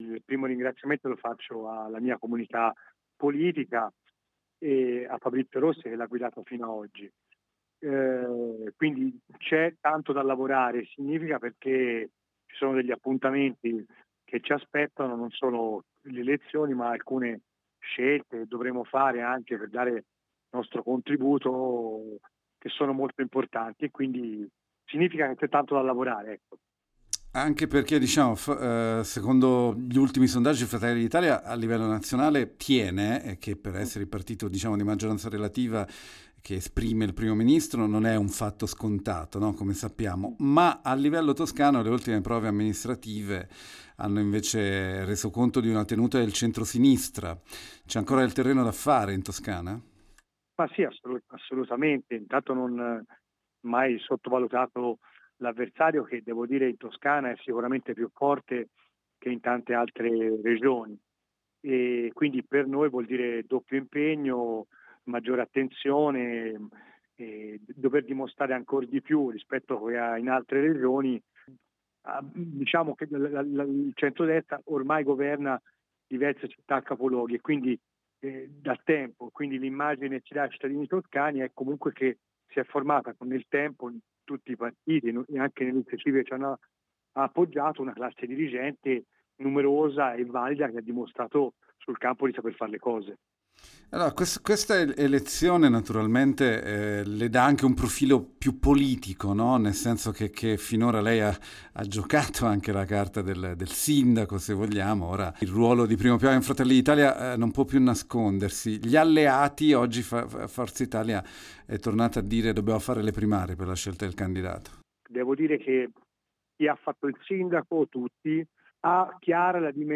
Lo abbiamo intervistato “Congratulazioni ad Alessandro Tomasi, nominato nuovo coordinatore regionale di FdI.